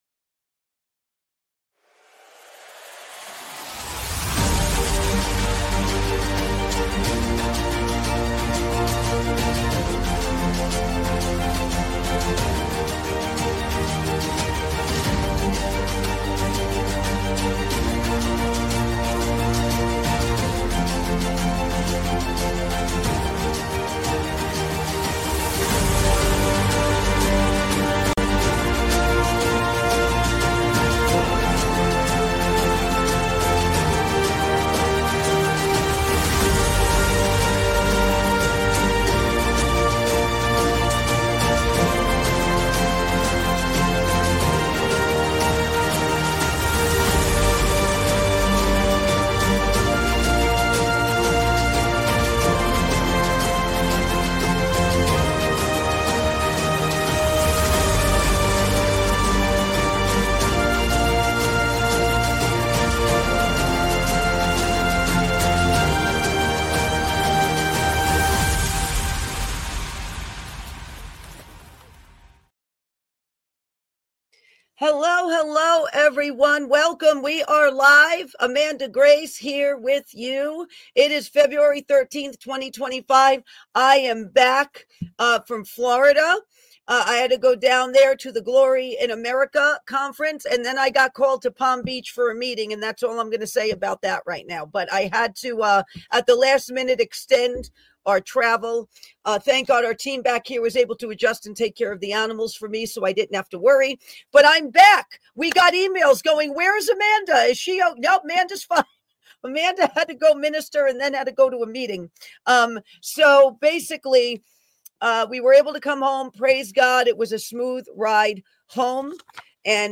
1 Jésus-Christ EST votre frère ET ami ! - Service en direct du dimanche 53:48